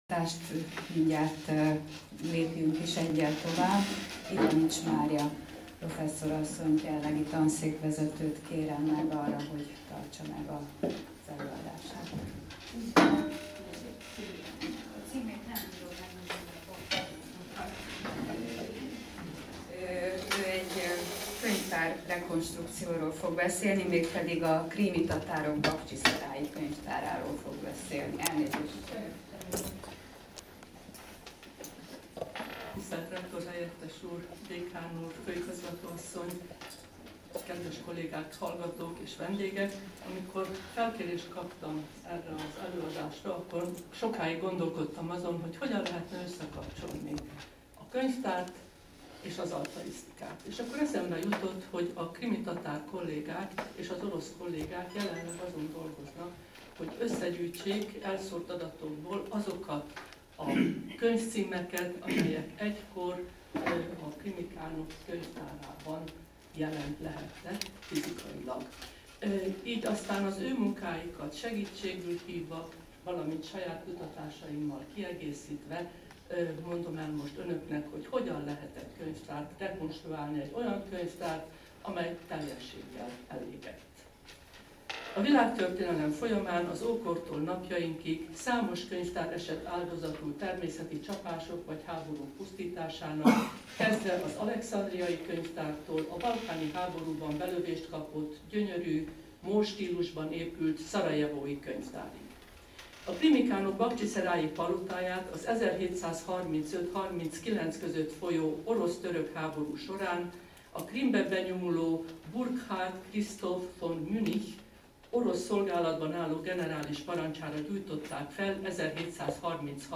Sinor-hagyaték ünnepélyes átadója, SZTE Klebelsberg Könyvtár